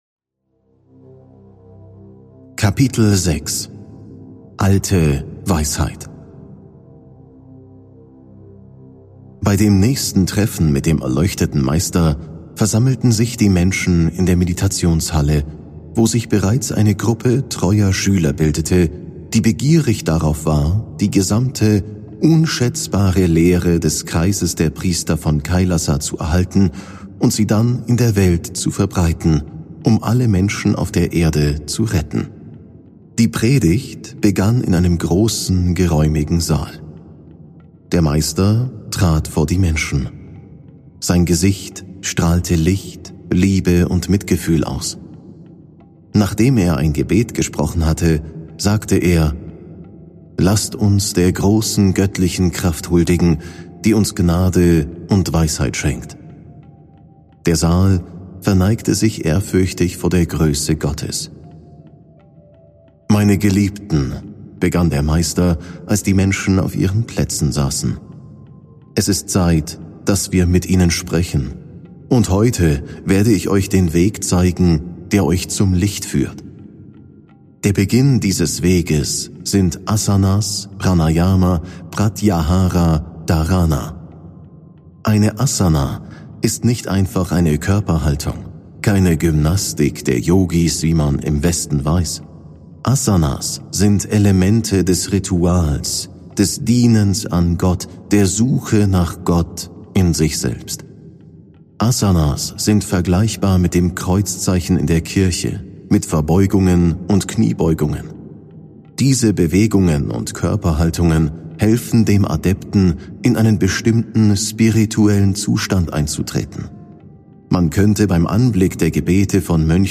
Hörbuch “Der Sternenbote” – Spirituelles Portal